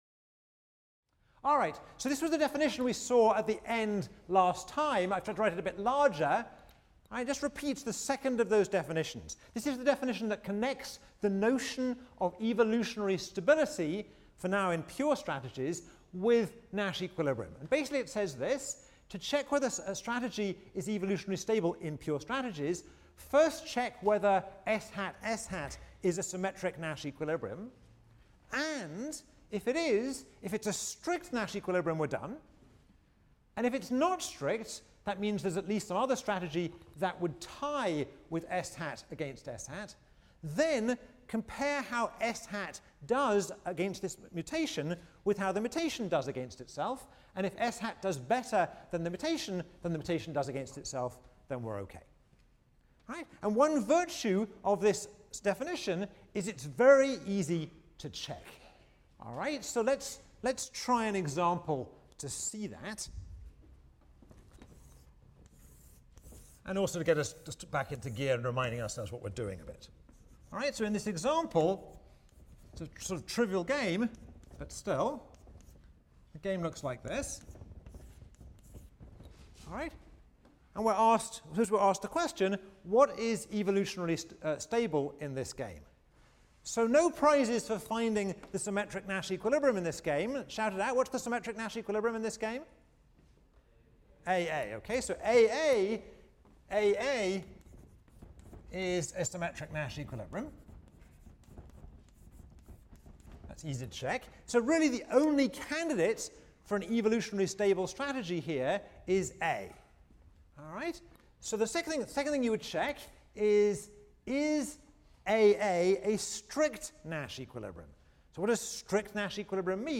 ECON 159 - Lecture 12 - Evolutionary Stability: Social Convention, Aggression, and Cycles | Open Yale Courses